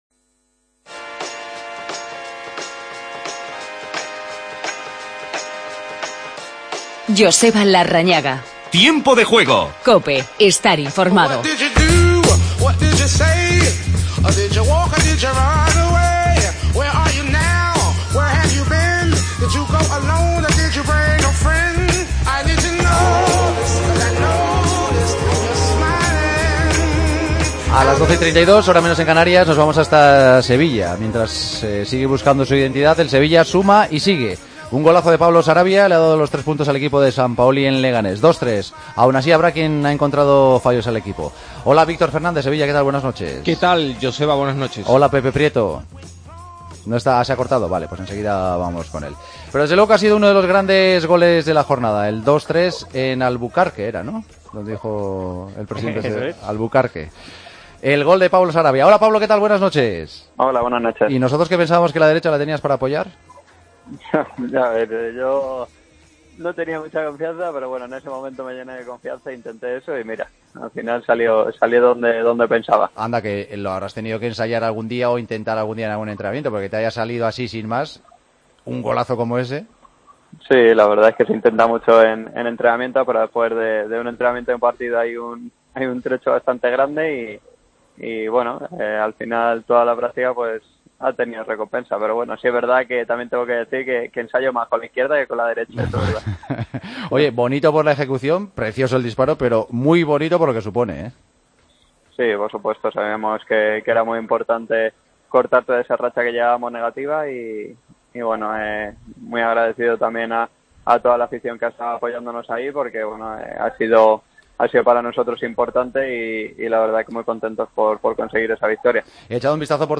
Redacción digital Madrid - Publicado el 16 oct 2016, 01:31 - Actualizado 14 mar 2023, 04:44 1 min lectura Descargar Facebook Twitter Whatsapp Telegram Enviar por email Copiar enlace El Sevilla vuelve a ganar fuera de casa 17 meses después. Entrevista a Sarabia, autor del gol de la victoria. Previa de los partidos de este domingo, donde destaca el Athletic-Real Sociedad. Hablamos con Toshack.